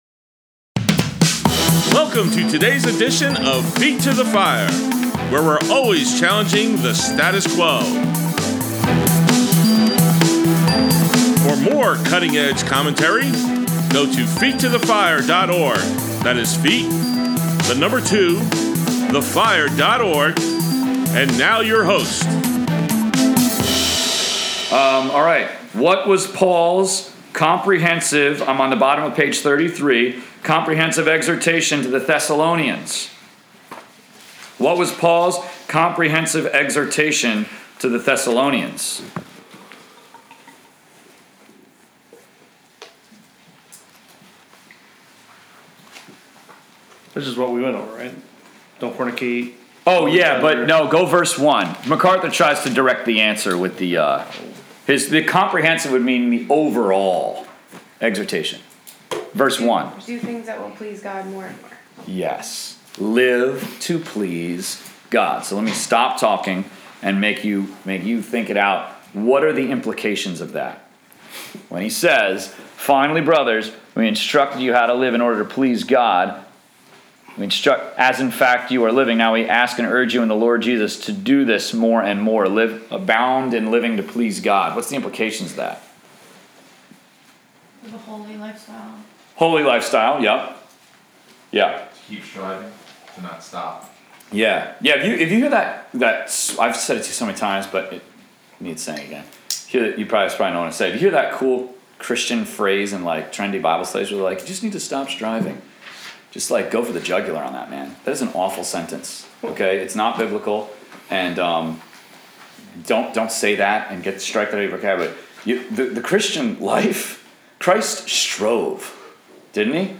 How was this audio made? Please note: At approximately minute 39, the recording program cut out momentarily and missed the reading and instruction from 1 Corinthians 10:1-10. It immediately picks up after, with no pause in the audio.